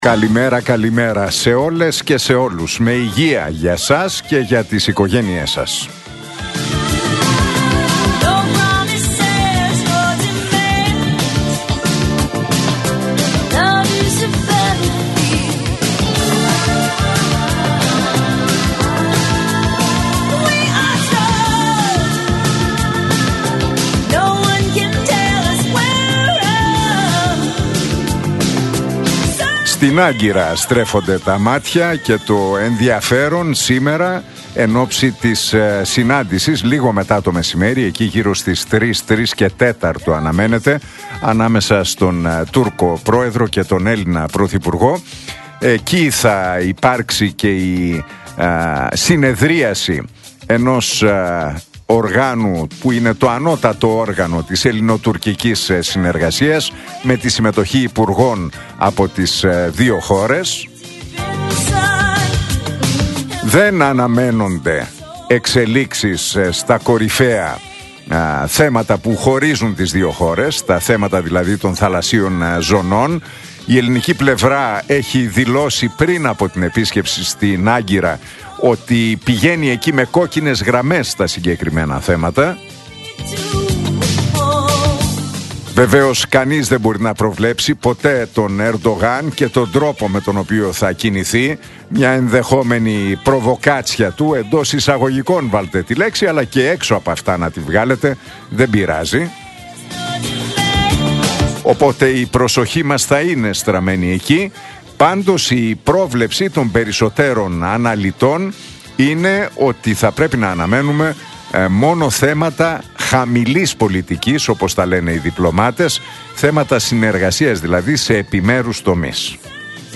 Ακούστε το σχόλιο του Νίκου Χατζηνικολάου στον ραδιοφωνικό σταθμό Realfm 97,8, την Τετάρτη 11 Φεβρουαρίου 2026.